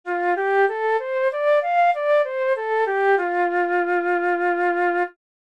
Here's what a major pentatonic scale sounds like:
pentatonic_scale.mp3